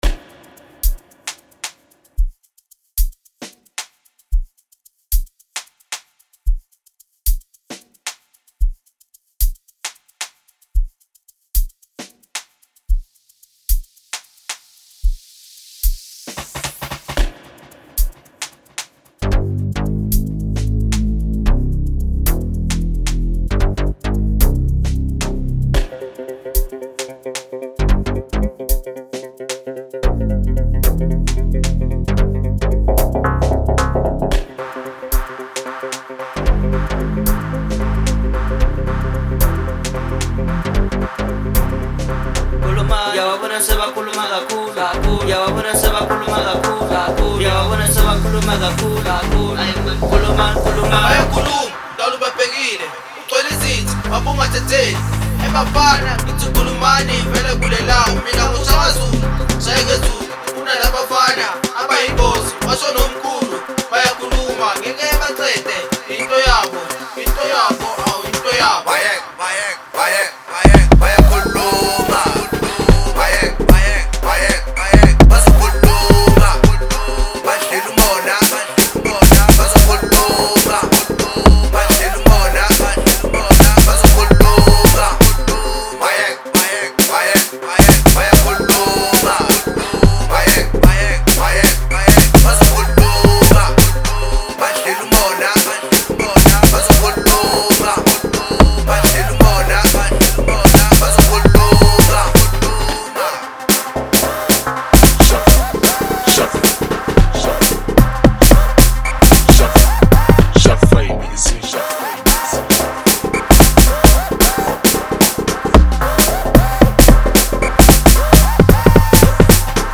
05:04 Genre : Amapiano Size